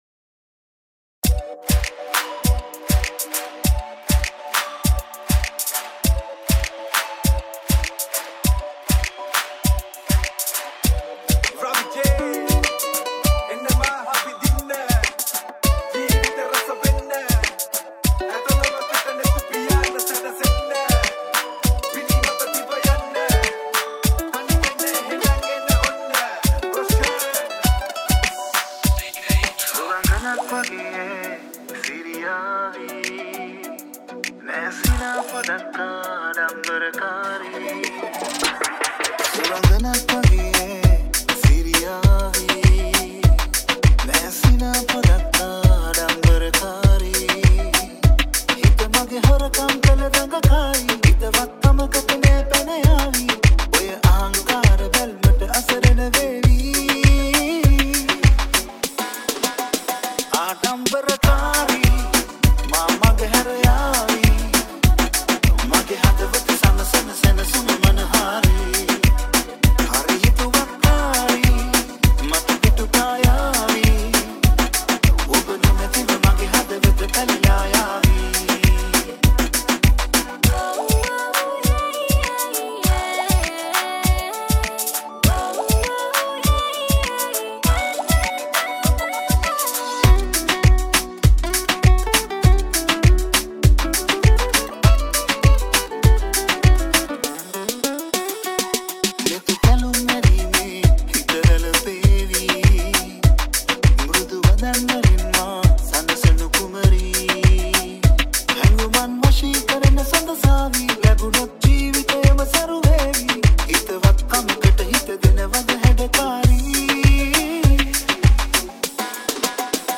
Moombahton